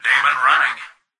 "Daemon running" excerpt of the reversed speech found in the Halo 3 Terminals.